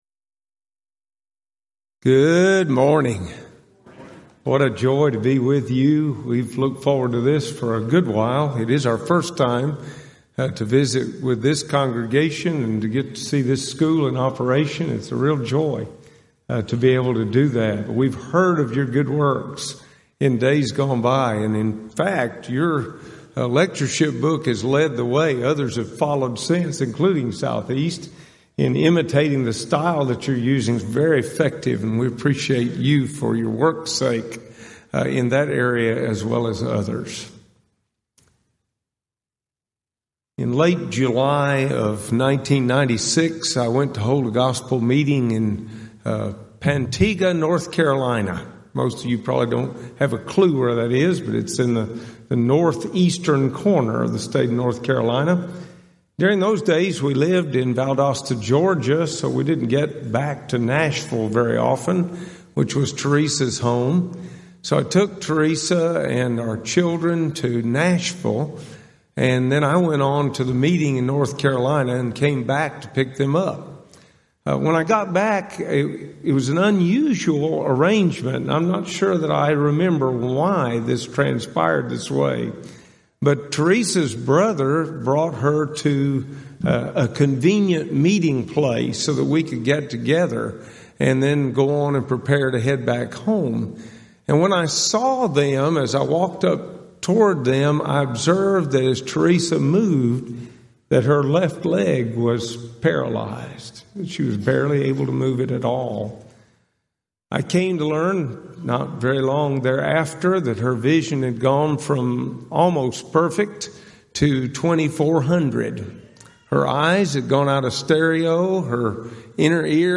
Event: 34th Annual Southwest Lectures Theme/Title: God's Help with Life's Struggles